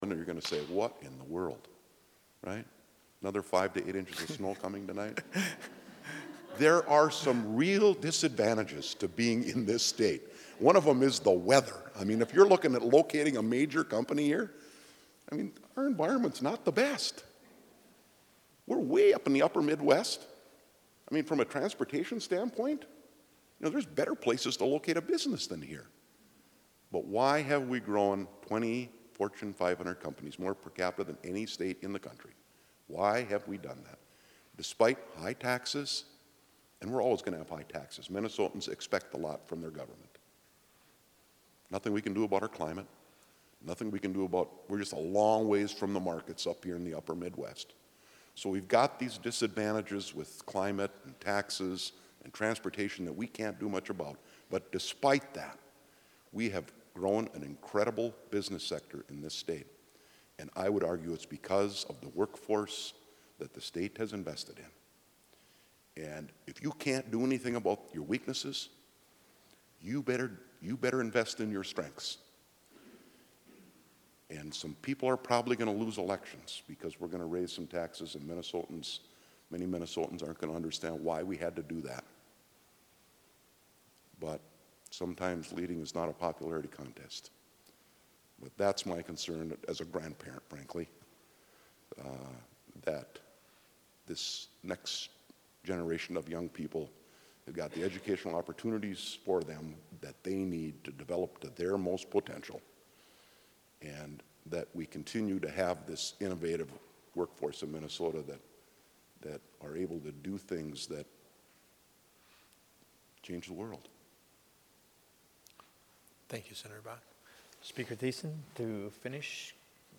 Budget Forum with Majority Leader Bakk and Speaker Thissen